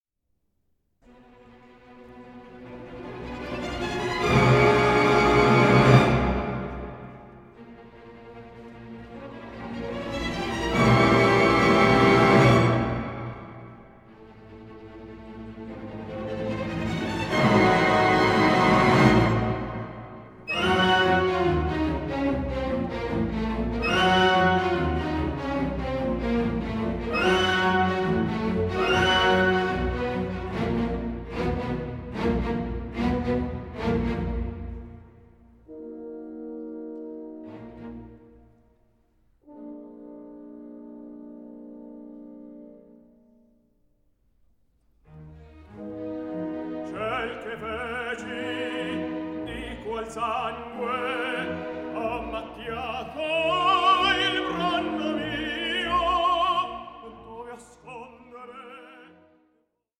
A WORLD FULL OF HEARTFELT TENDERNESS AND FIRE-LADEN DRAMA
tenor